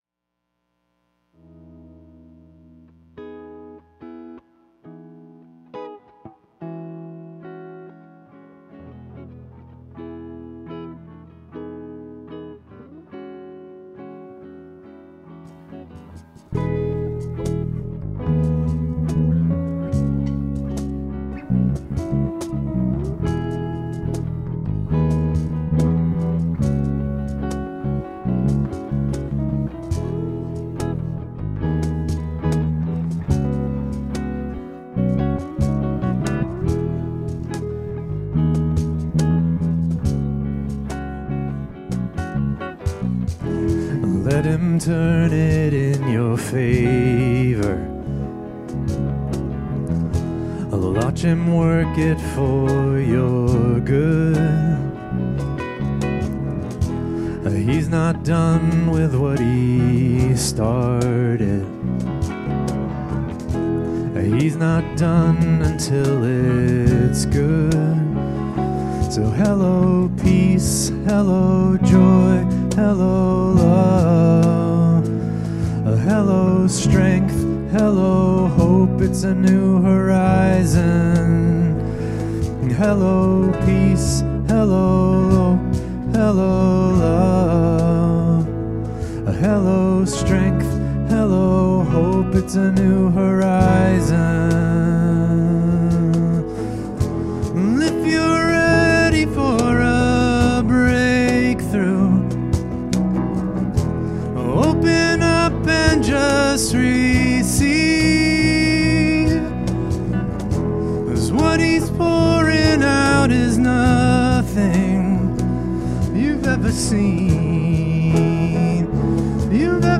Worship 2025-01-26